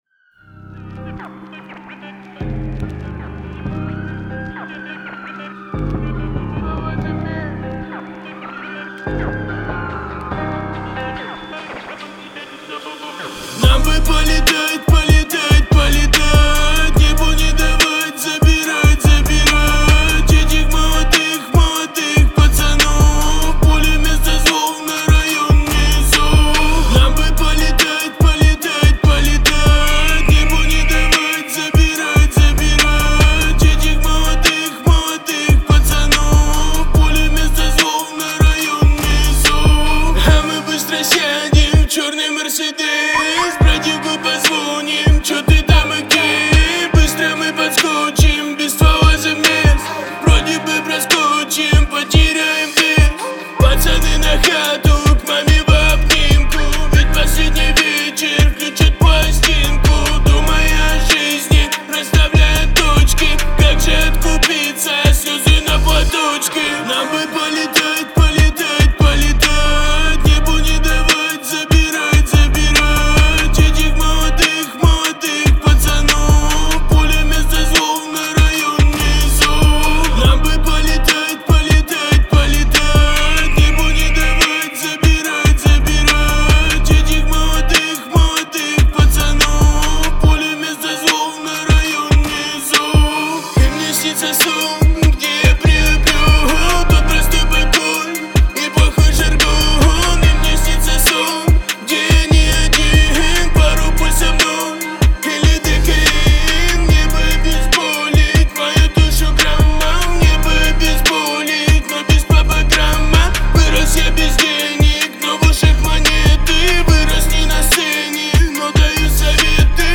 мощная рок-композиция